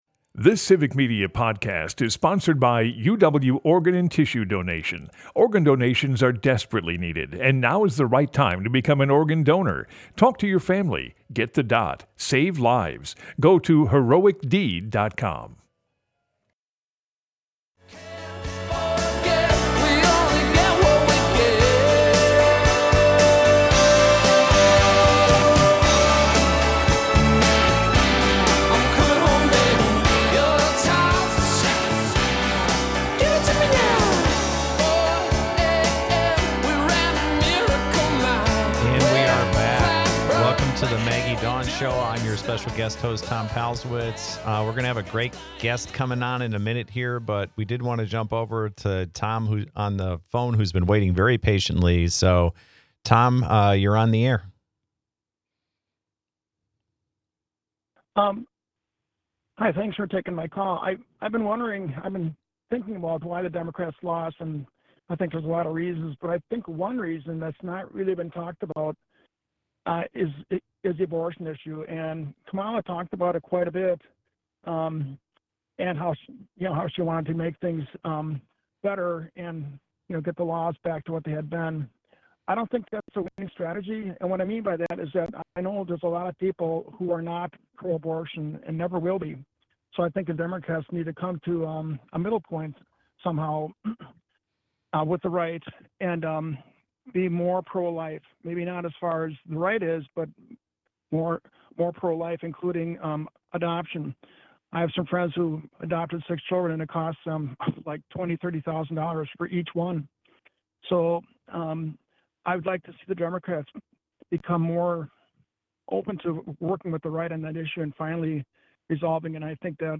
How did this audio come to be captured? Broadcasts live, 2 - 4 p.m. across Wisconsin.